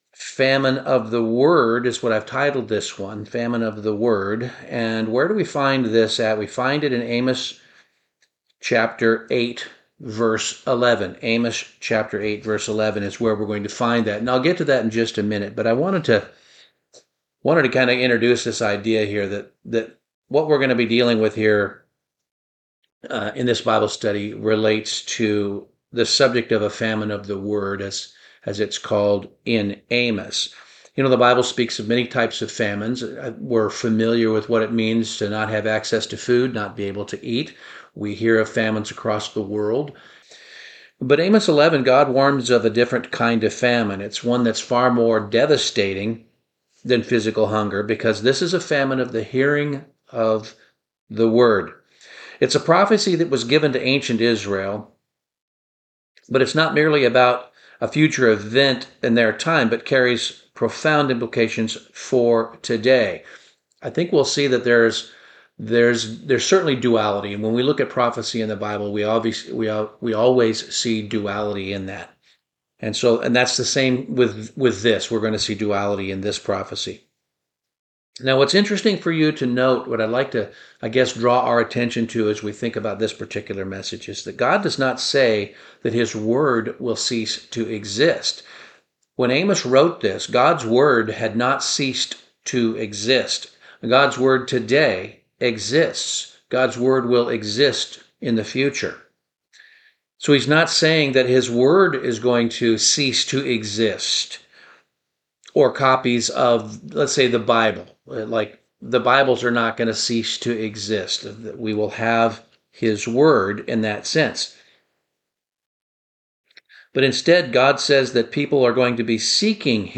Bible Study - Famine of the Word